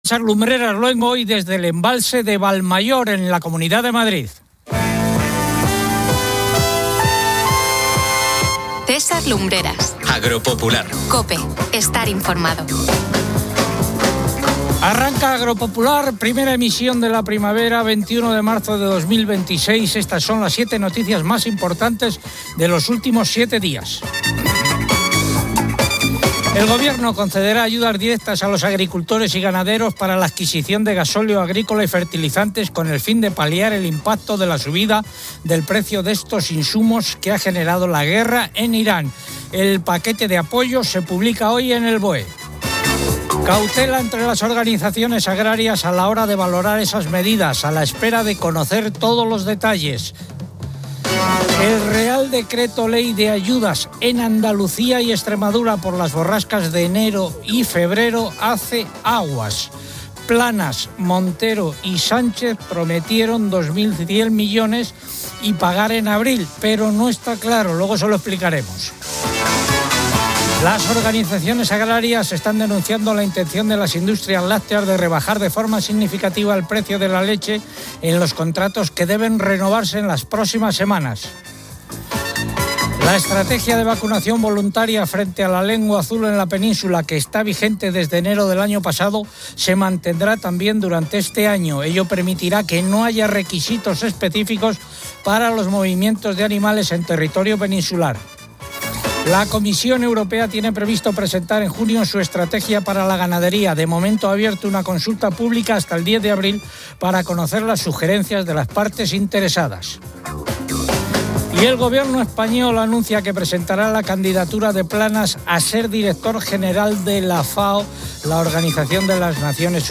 Agropopular 08:30H | 21 MAR 2026 | Agropopular El programa Agropopular, que arranca la primavera desde el embalse de Valmayor, presenta las nuevas ayudas directas del gobierno a agricultores y ganaderos para paliar el impacto de los altos precios del gasóleo y los fertilizantes, una medida que genera cautela y críticas por su posible insuficiencia entre las organizaciones agrarias. Un tema central es la seria afectación a la rentabilidad y competitividad de los agricultores españoles por las limitaciones en el uso de productos fitosanitarios, fruto de las políticas verdes de la PAC, a diferencia de otros países europeos. El espacio destaca la excelente situación de las reservas de agua en los pantanos nacionales, que superan el 83% de su capacidad al inicio de la primavera, y los altos niveles de los embalses del Canal de Isabel II.